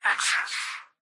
"Access" excerpt of the reversed speech found in the Halo 3 Terminals.